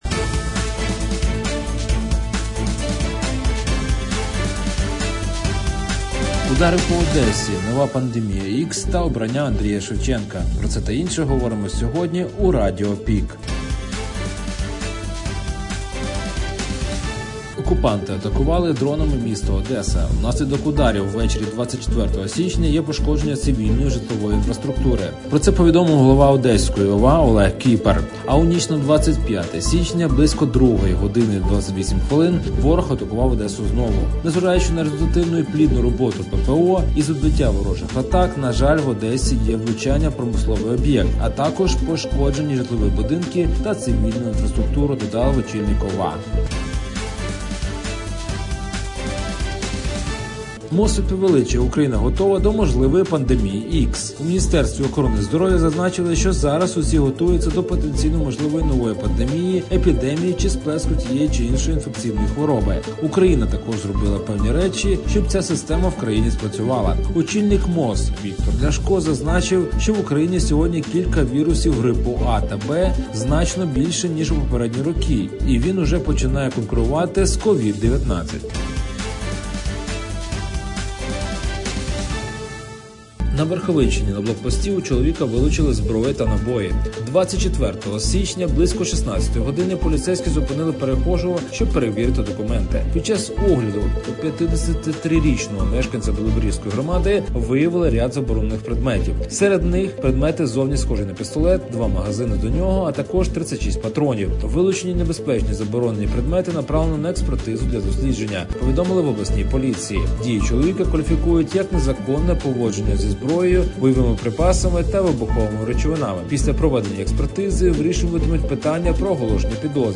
Пропонуємо актуальне за день у радіоформаті.